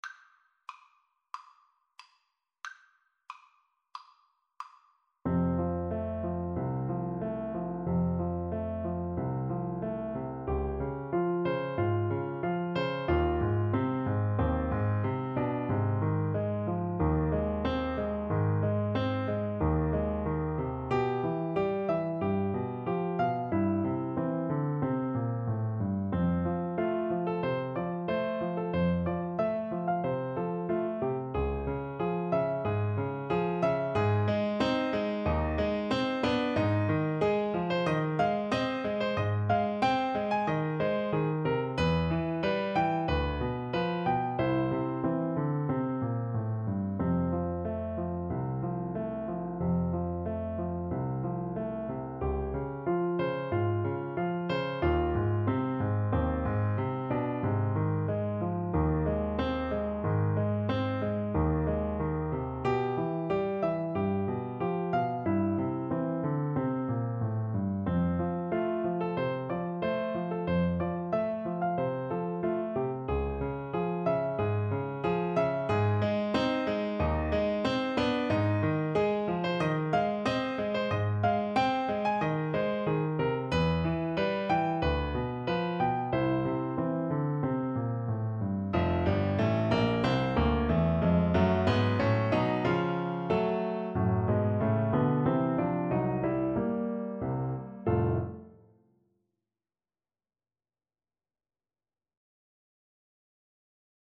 Play (or use space bar on your keyboard) Pause Music Playalong - Piano Accompaniment Playalong Band Accompaniment not yet available reset tempo print settings full screen
F major (Sounding Pitch) G major (Clarinet in Bb) (View more F major Music for Clarinet )
Andantino =92 (View more music marked Andantino)
4/4 (View more 4/4 Music)
Classical (View more Classical Clarinet Music)